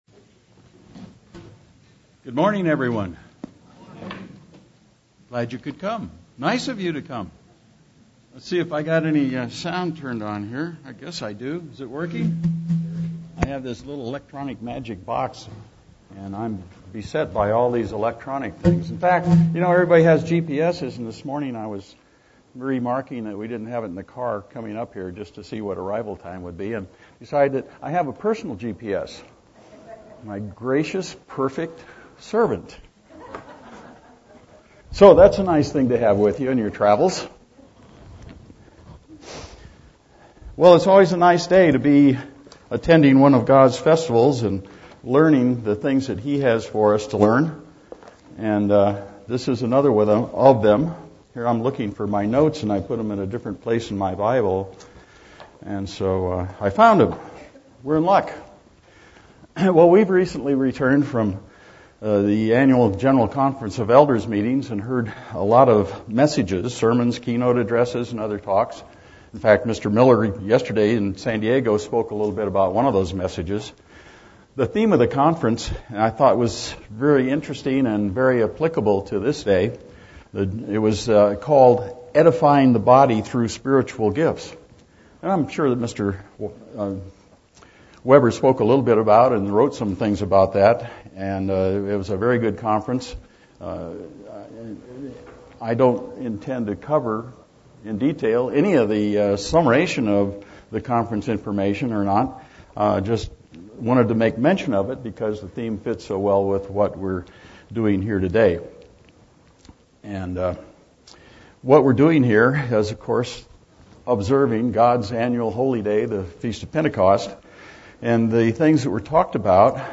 God wants us to use the gifts given to us because they benefit the whole body of Christ. The importance of the Holy Spirit and many gifts is looked at but also the command to not quench the spirit on this Pentecost day message.
Given in San Diego, CA
UCG Sermon Studying the bible?